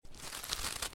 tbd-station-14/Resources/Audio/Effects/Footsteps/snowstep.ogg
snowstep.ogg